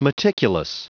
Prononciation du mot meticulous en anglais (fichier audio)
Prononciation du mot : meticulous